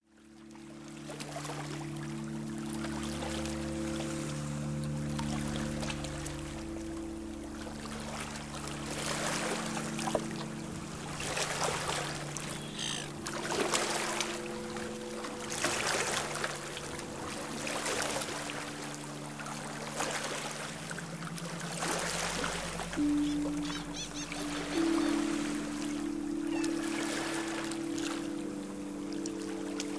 Calm sea - soothing sounds
Within minutes, you find yourself in a perfect world , where your brain is stimulated and soothed by the sounds of nature.
calmsea.mp3